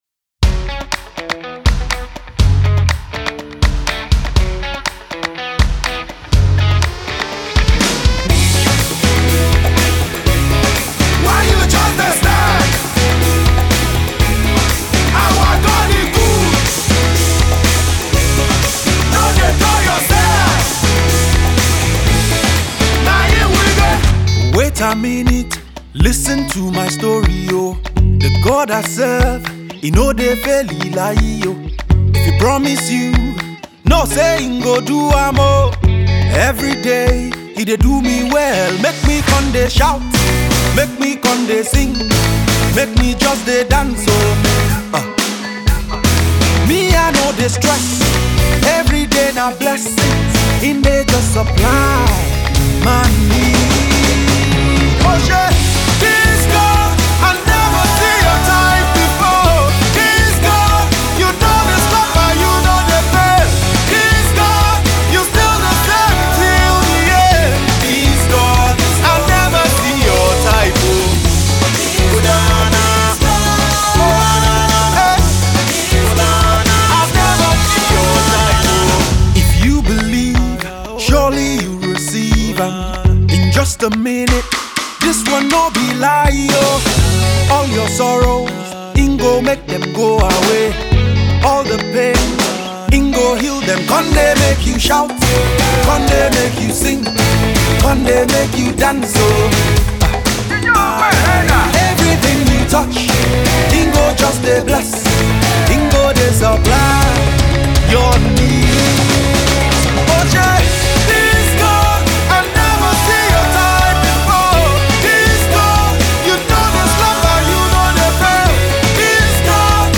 Contemporary Christian musician